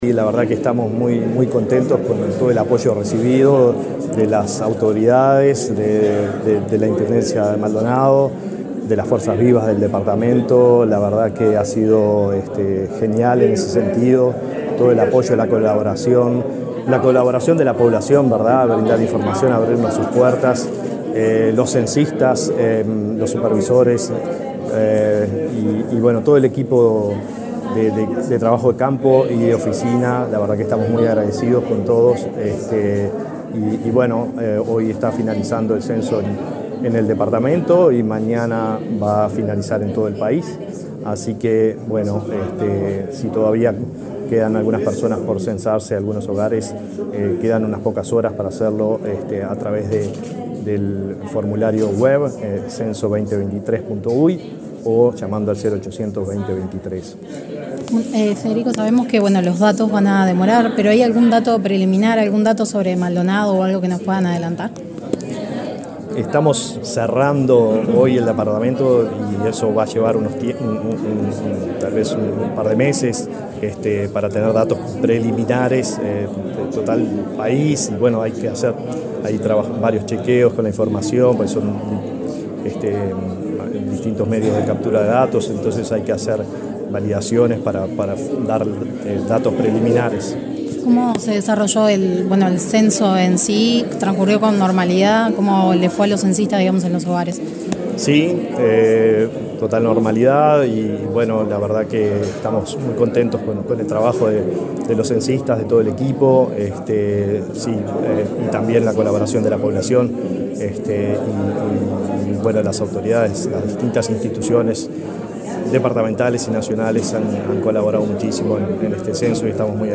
Declaraciones del subdirector del INE, Federico Segui
Luego dialogó con la prensa.